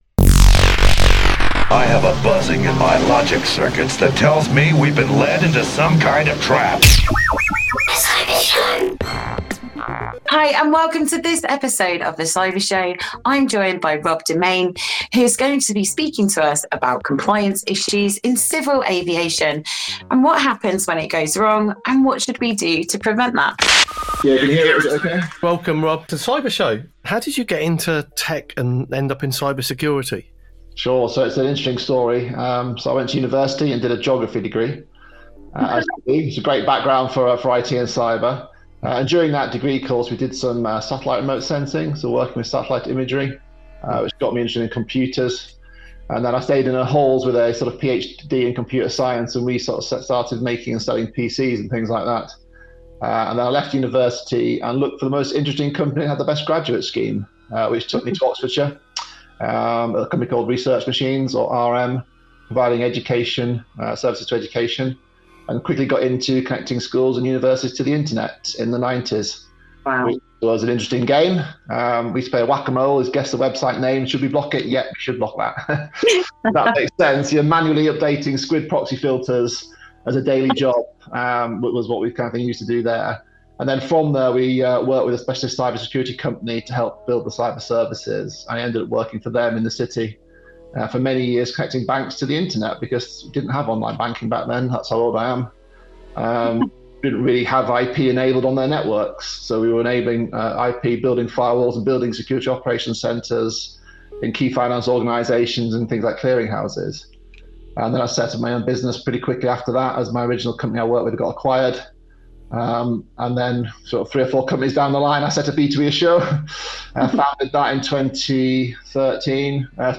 Categories: Audio Only In The Chair Interview Free Open Source Software Health and Technology Privacy, Dignity, Personal Data Distributed and Federated Systems Science, Research, Research Methods